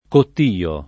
vai all'elenco alfabetico delle voci ingrandisci il carattere 100% rimpicciolisci il carattere stampa invia tramite posta elettronica codividi su Facebook cottio [ kott & o ] s. m. — a Roma, vendita del pesce all’incanto